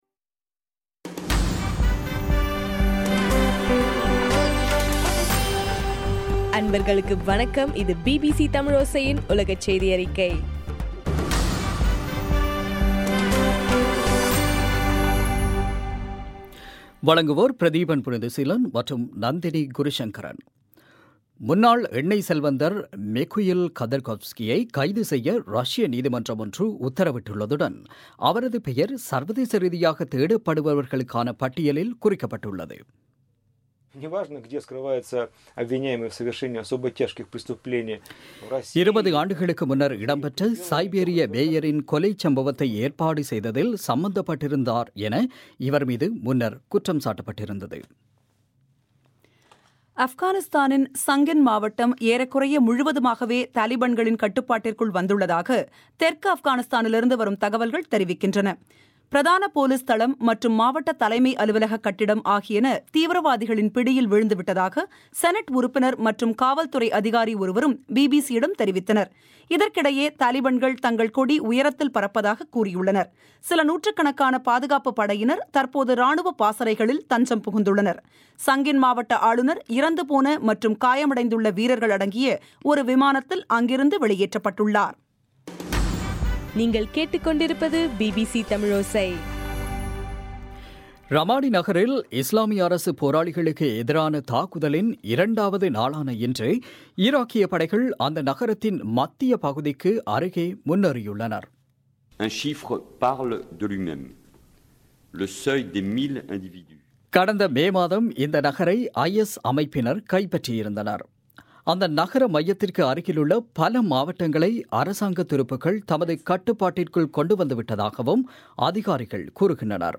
டிசம்பர் 23, 2015 பிபிசி தமிழோசையின் உலகச் செய்திகள்